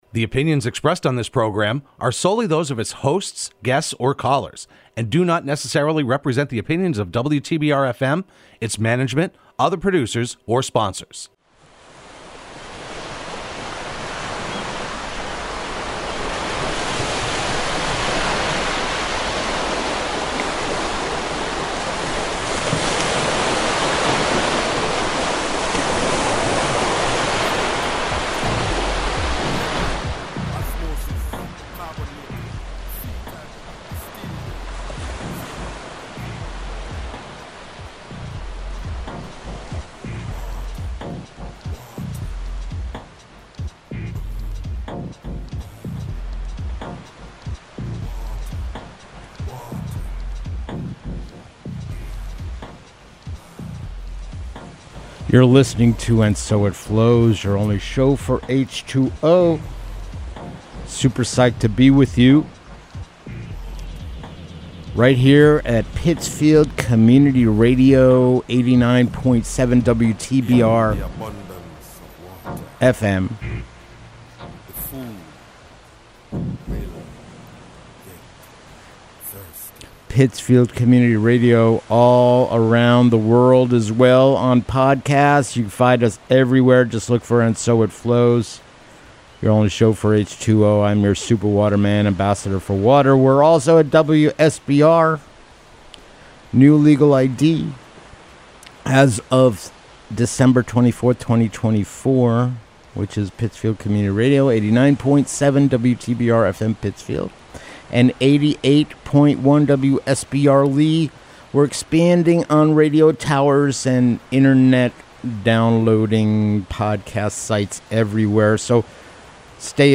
speaks with Lee, MA select board member Sean Regnier about the recent Boston rally demanding GE rethink its Housatonic River cleanup. At issue is GE’s plan to build a PCB landfill in Lee. The rally demanded GE to rid all traces of PCBs responsibly and completely.